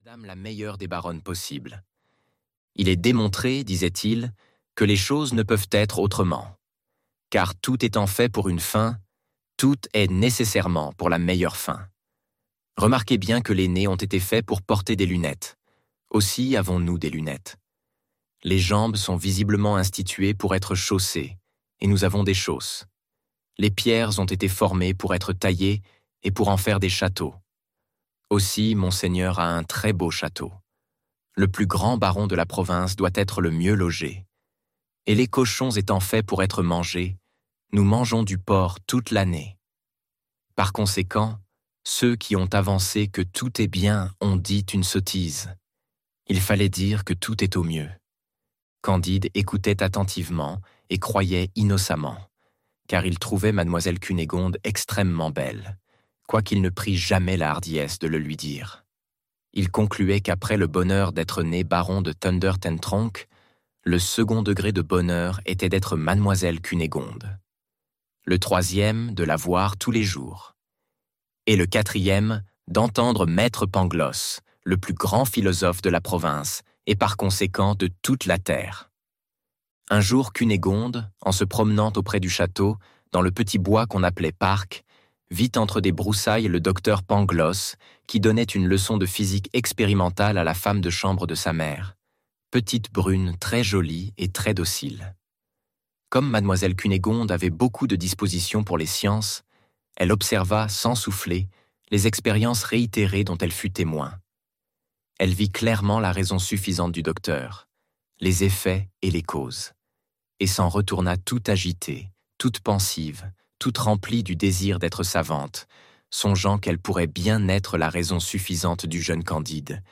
Candide, ou l'Optimisme - Livre Audio
Extrait gratuit